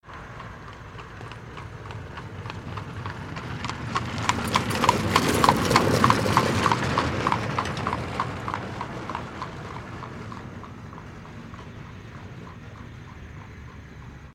دانلود آهنگ کالسکه اسب از افکت صوتی اشیاء
جلوه های صوتی
دانلود صدای کالسکه اسب از ساعد نیوز با لینک مستقیم و کیفیت بالا